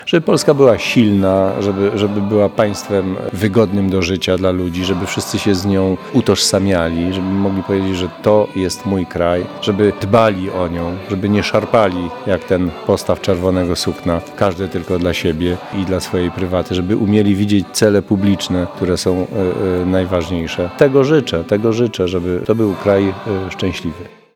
Tradycją już jest, iż w przeddzień 11 listopada Muzeum Regionalne „Pałacyk Oborskich” w Mielcu zaprasza do udziału w Śpiewankach patriotycznych.
Tak było i tym razem, kilkadziesiąt osób zaśpiewało znane i lubiane przez wszystkich pieśni patriotyczne.